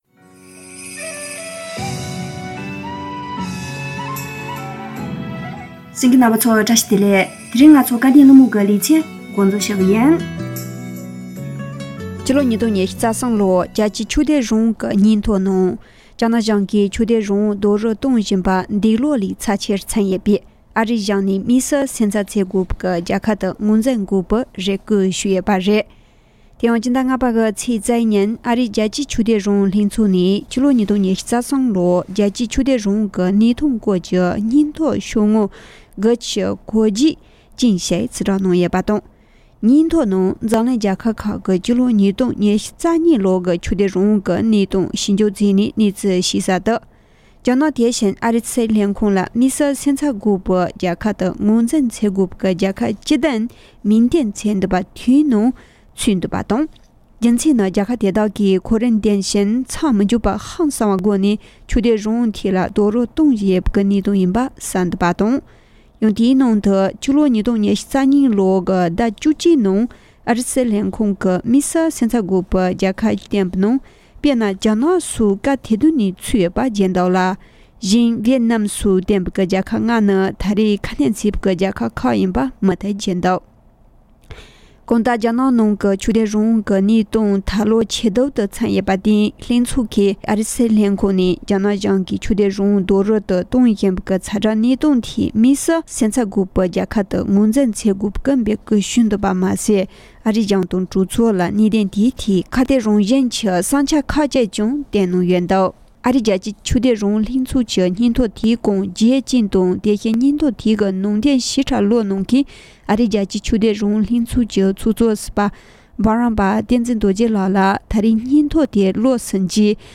སྐབས་དོན་གླེང་མོལ་གྱི་ལེ་ཚན་ནང་དུ།